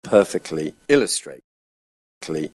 It’s far more common, even in quite careful speech, for native speakers to articulate the t weakly or not at all, so that strictly can sound like strickly:
Likewise perfectly, exactly and directly can sound like perfeckly, exackly and direckly: